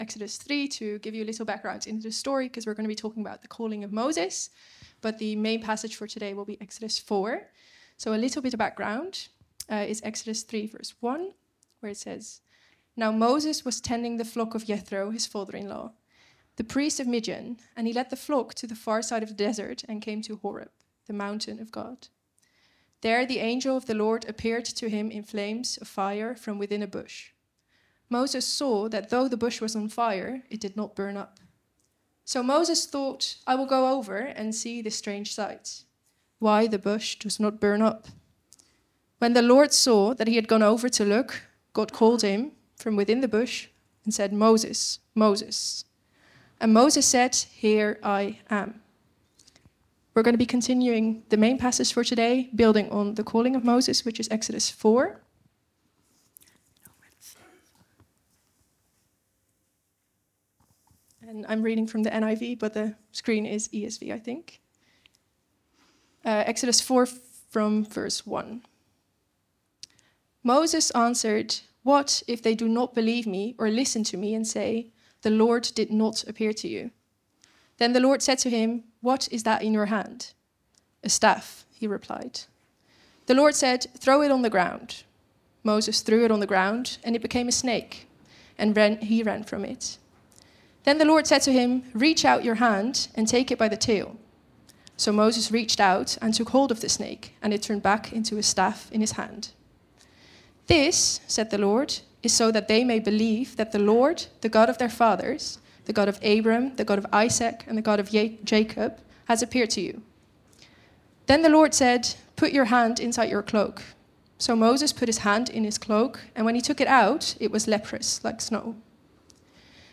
Vineyard Groningen Sermons What's in Your Hand?